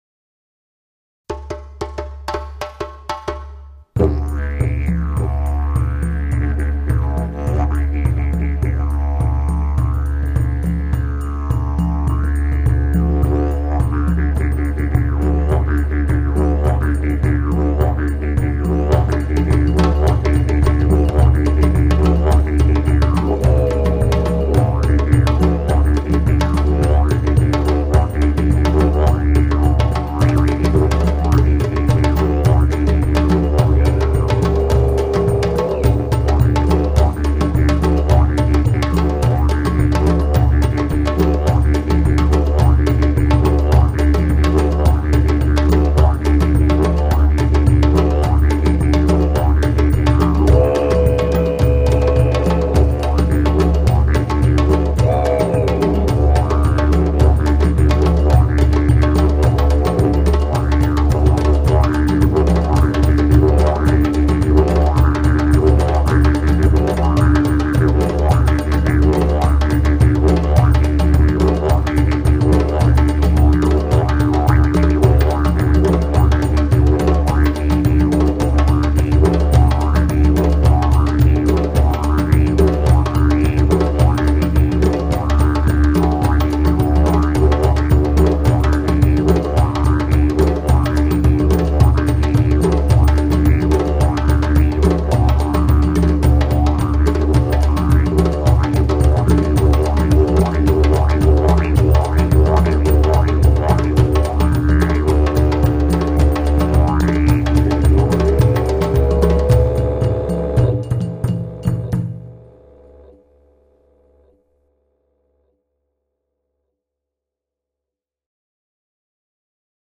Hier einige Kostproben der music for a documentary film: